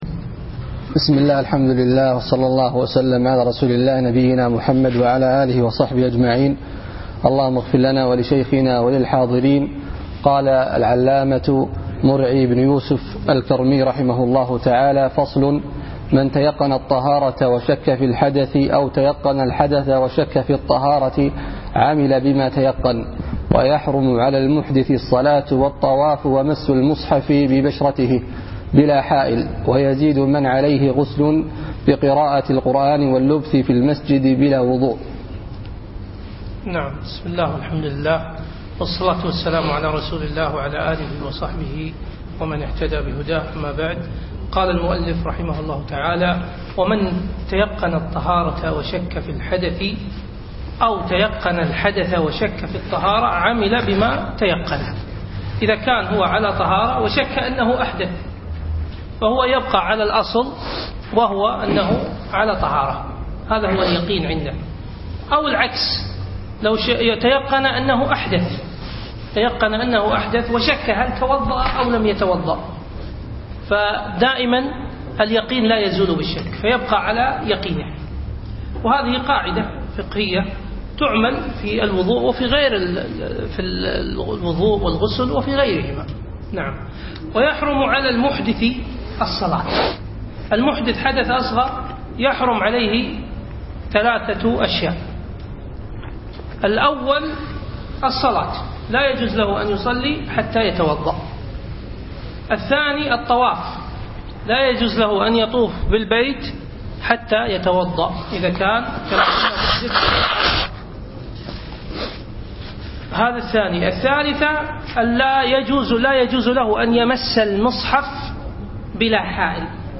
أقيم الدرس يوم السبت 7 3 2015 بعد المفرب في مسجد سالم العلي الفحيحيل